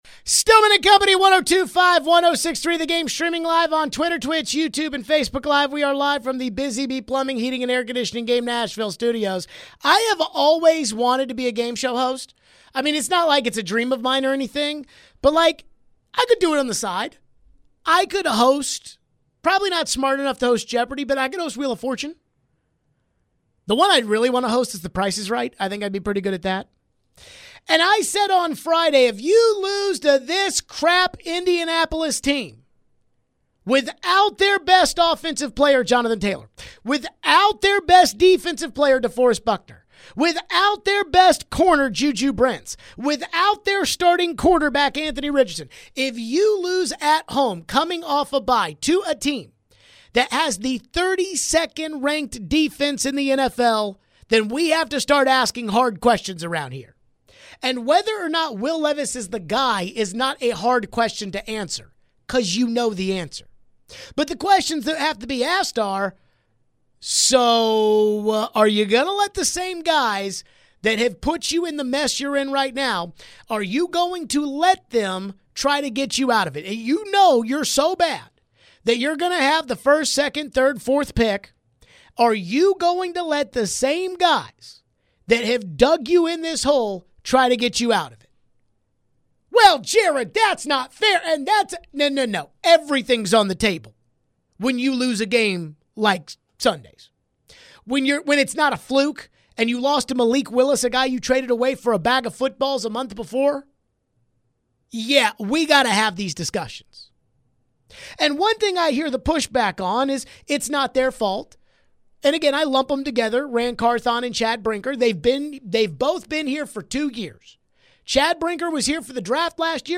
We play a little game show to open this hour called, Bust or Hush as we go through some of the past offseason moves for the Titans. Should the Titans have tried harder to keep Derrick Henry this offseason?
We take your phones.